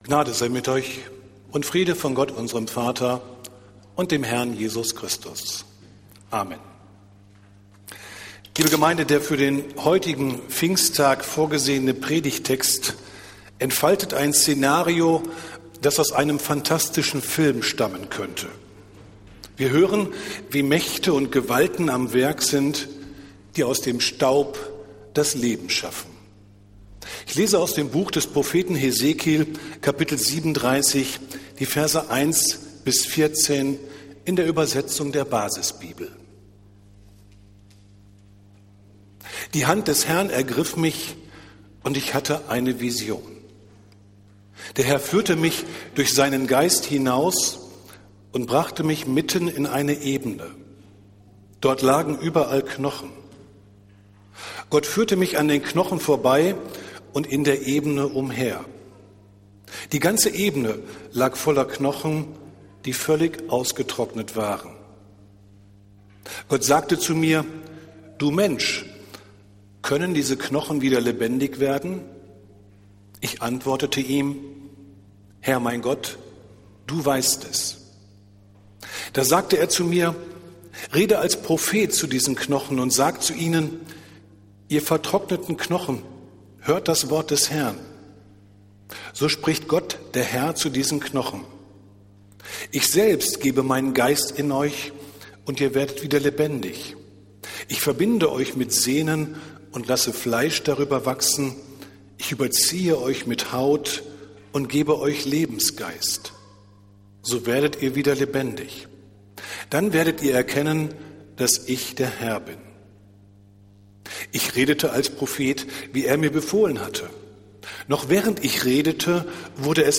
Predigt des Gottesdienstes aus der Zionskirche am Pfingstsonntag, den 19. Mai 2024